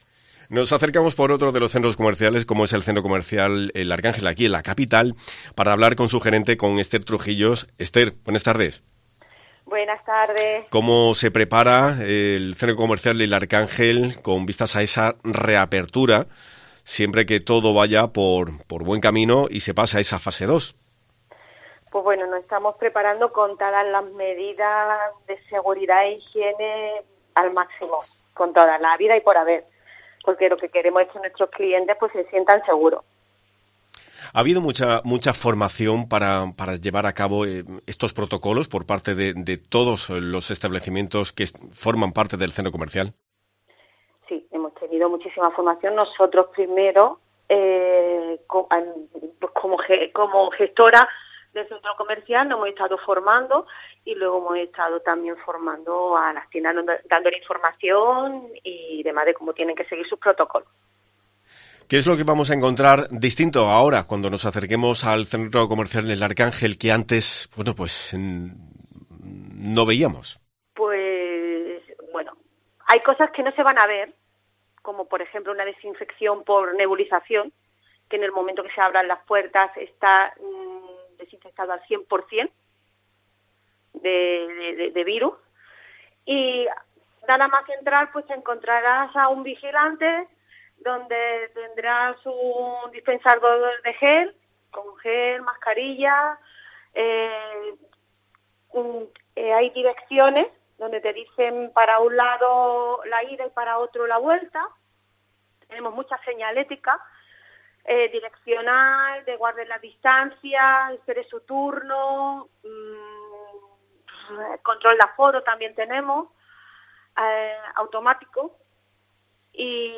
responde las preguntas